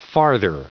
Prononciation du mot farther en anglais (fichier audio)
Prononciation du mot : farther